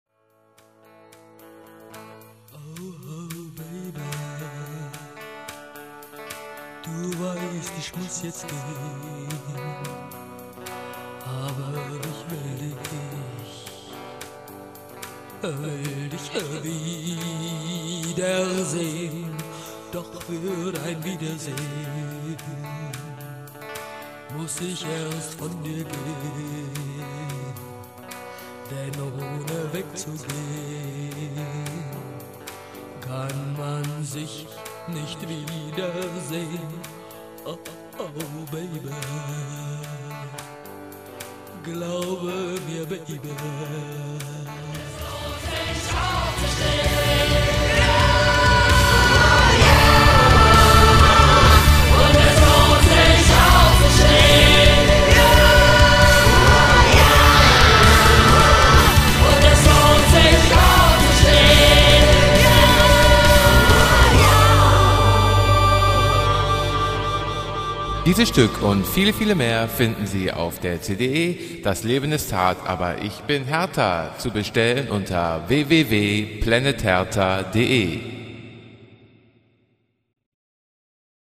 in Studio-Fassung